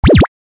جلوه های صوتی
دانلود صدای موس 7 از ساعد نیوز با لینک مستقیم و کیفیت بالا